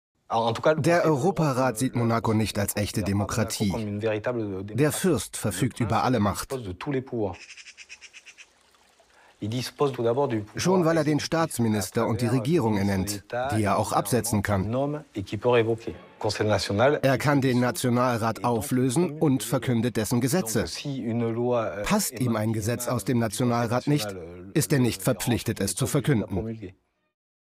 sehr variabel
Mittel minus (25-45)
Commercial (Werbung)